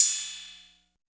16 808 CYM.wav